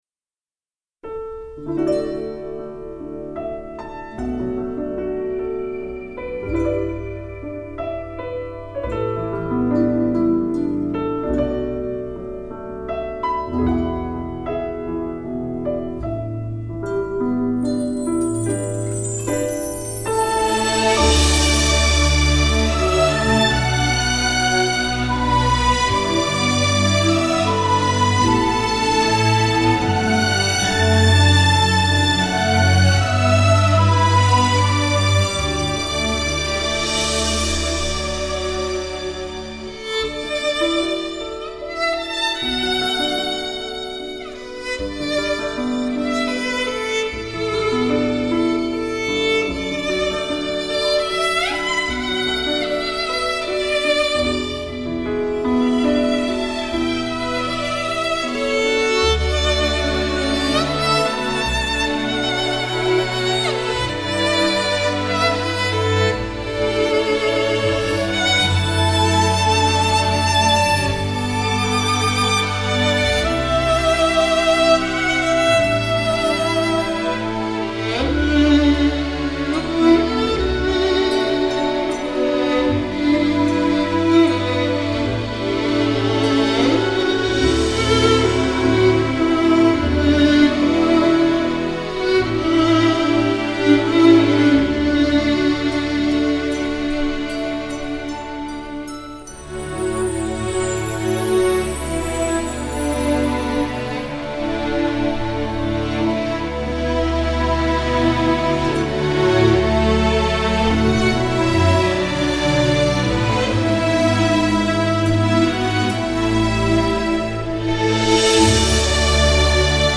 3周前 纯音乐 5